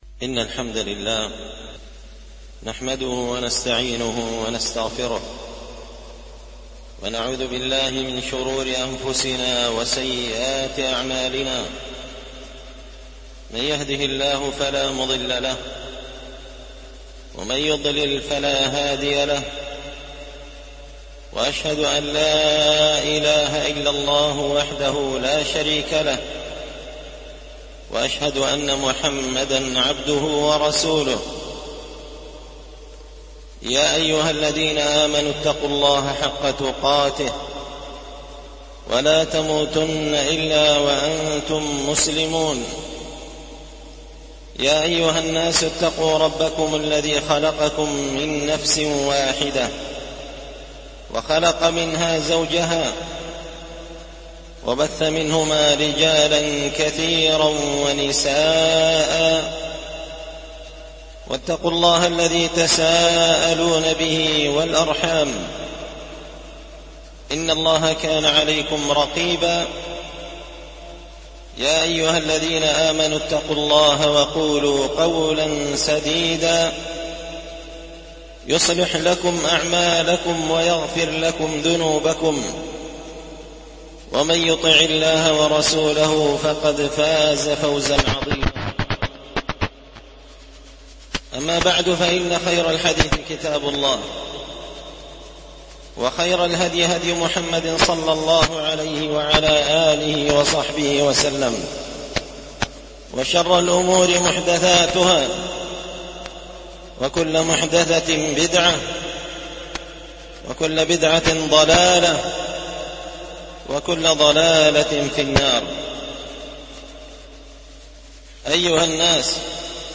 خطبة جمعة بعنوان الوقاية من أسباب الغواية